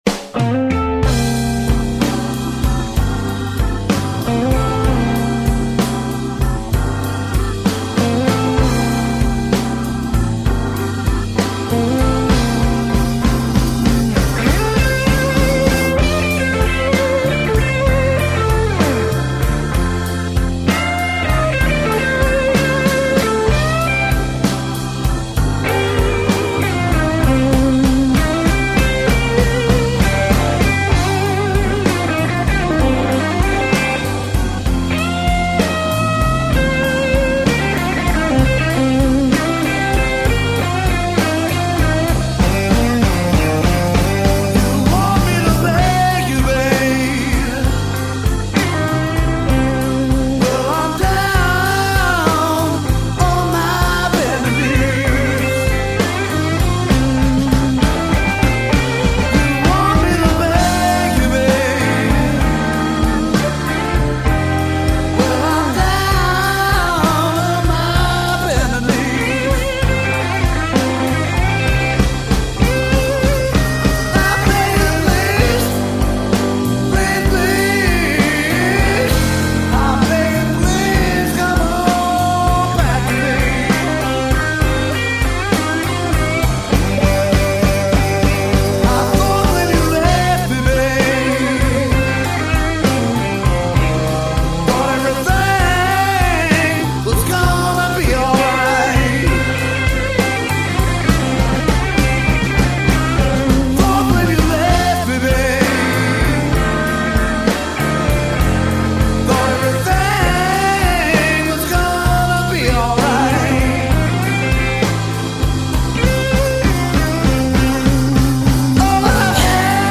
Recorded Summer 1999
vocal, guitar
Hammond organ, Wurlitzer piano
bass guitar
drums